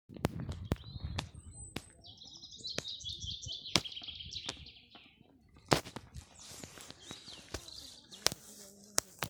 Birds -> Finches ->
Chaffinch, Fringilla coelebs
StatusSinging male in breeding season